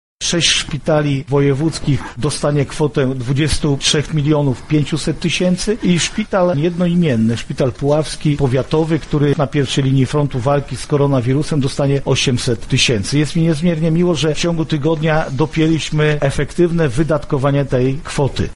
– mówi Jarosław Stawiarski, Marszałek Województwa Lubelskiego